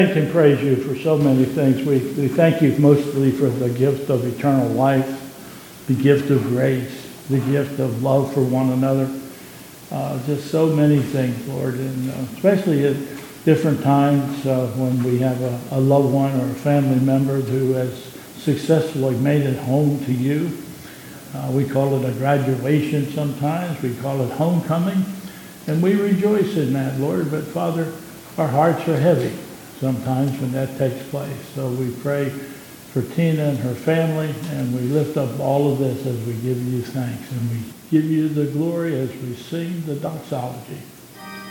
2022 Bethel Covid Time Service
...followed by a prayer